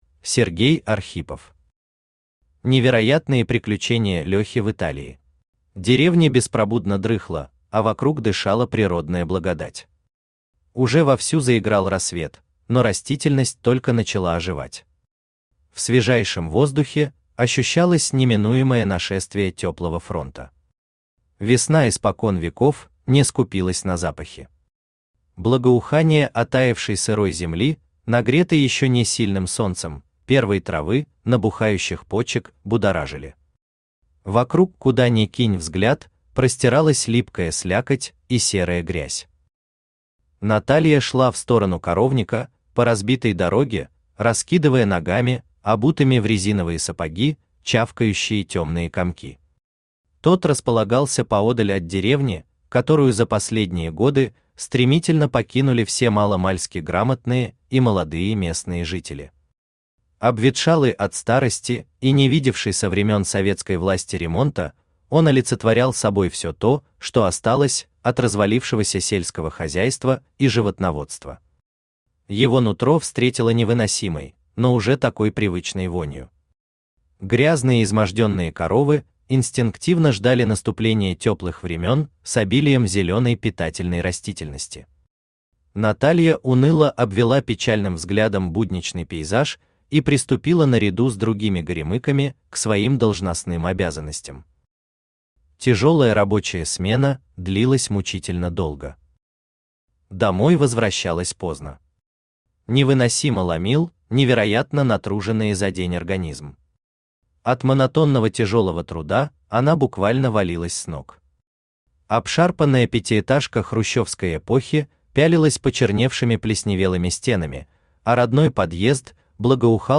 Аудиокнига Невероятные приключения Лехи в Италии | Библиотека аудиокниг
Aудиокнига Невероятные приключения Лехи в Италии Автор Сергей Александрович Архипов Читает аудиокнигу Авточтец ЛитРес.